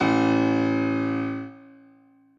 b_basspiano_v100l1o2a.ogg